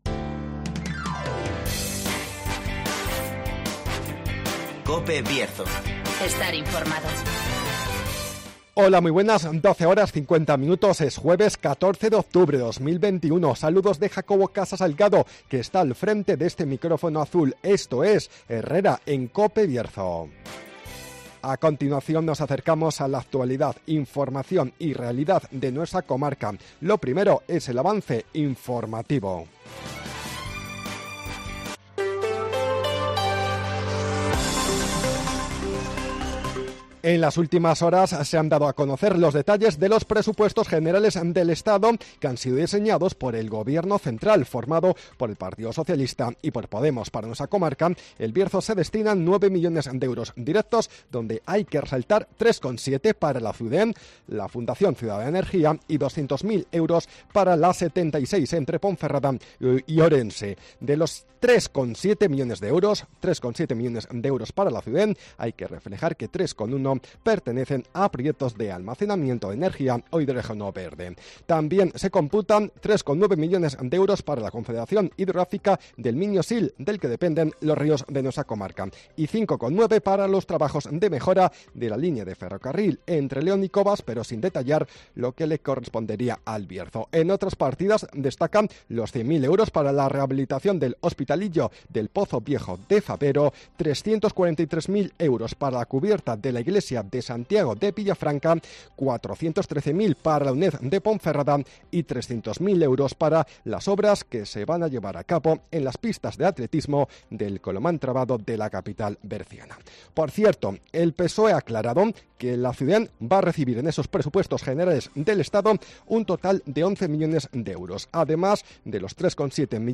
Avance informativo, El Tiempo y Agenda.